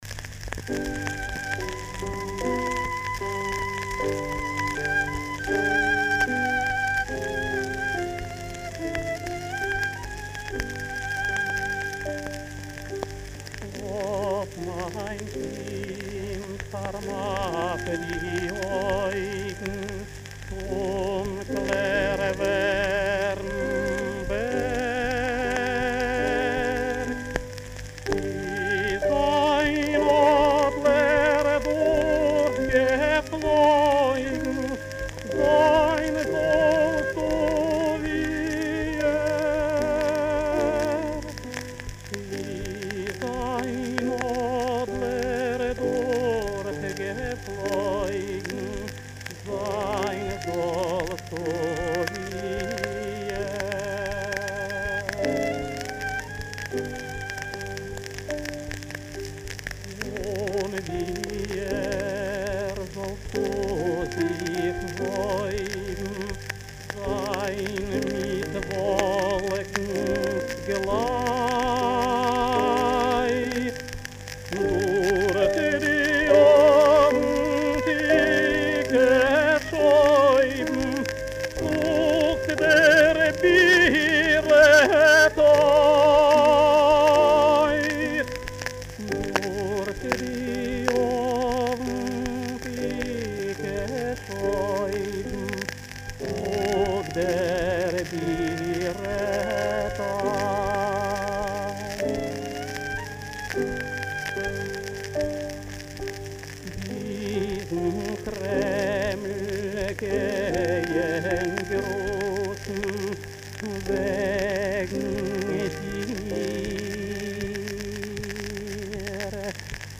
Описание: Лирико-патриотическая еврейская песня.
ф-но